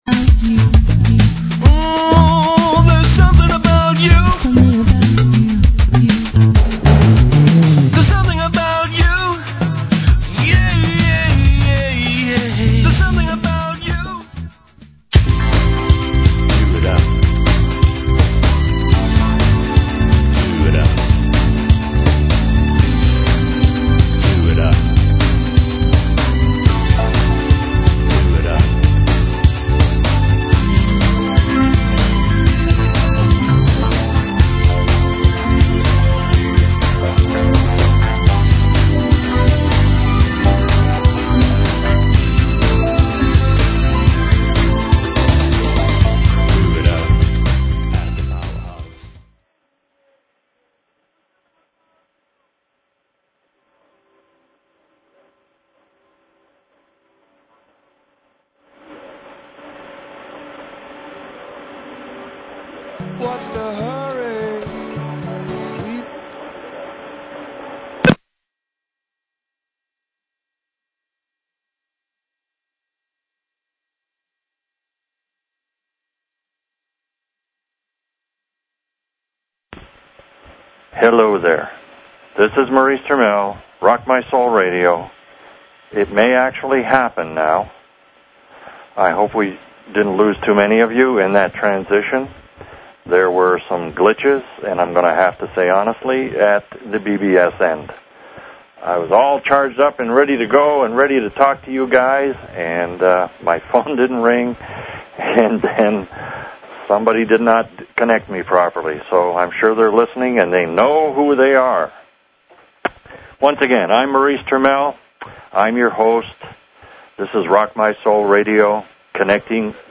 Talk Show Episode, Audio Podcast, Rock_My_Soul_Radio and Courtesy of BBS Radio on , show guests , about , categorized as
Passion is energy moving us forward toward that purpose for which we were born. We introduced new music in this broadcast and a Guided Meditation to help Connect Us to Source.